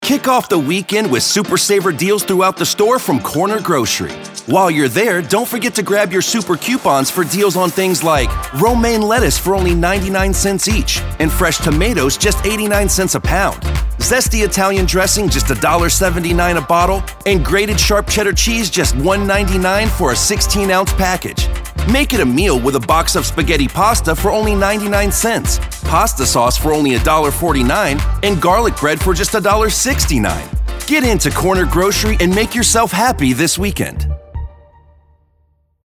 announcer, confident, friendly, millennial, perky, professional, promo, upbeat